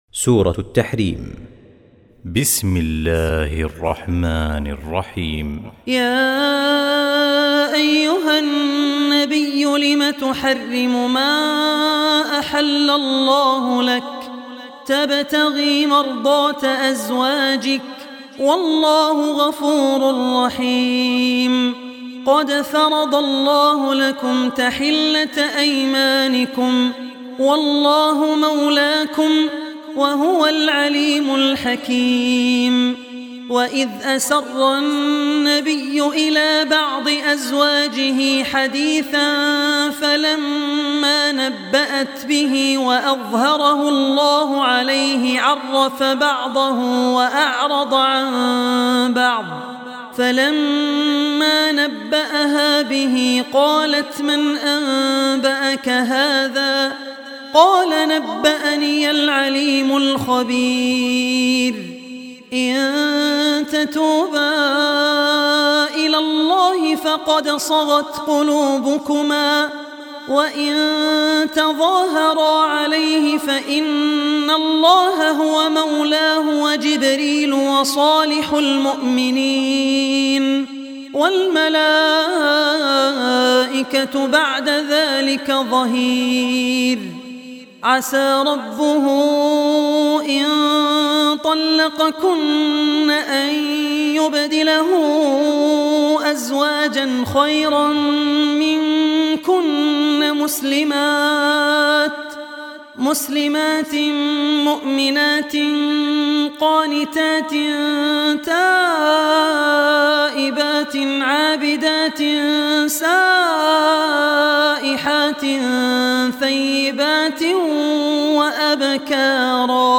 Surah Tahrim, listen online mp3 tilawat / recitation in Arabic recited by Sheikh Abdul Rehman Al Ossi.